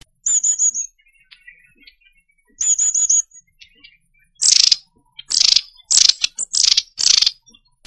Mésanges à longue queue, aegithalos caudatus